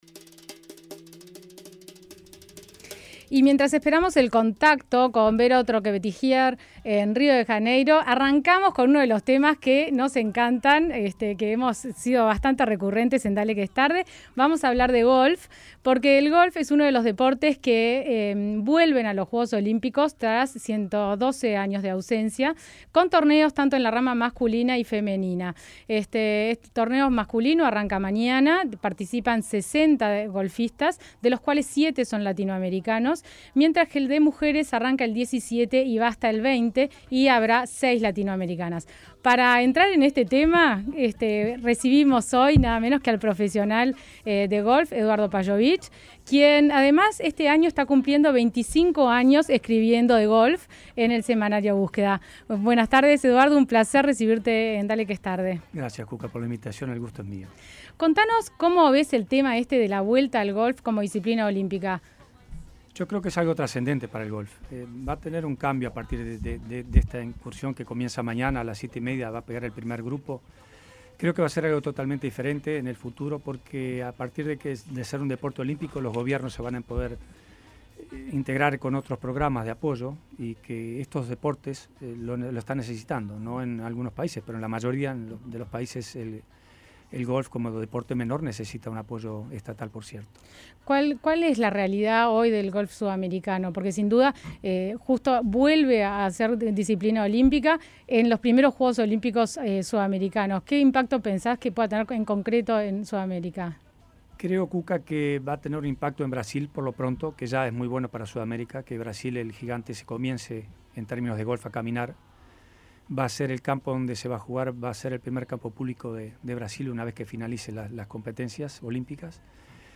En charla con Dale que es Tarde